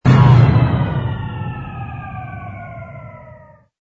engine_br_cruise_stop.wav